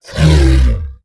MONSTERS_CREATURES
ORCH_Grunt_01_mono.wav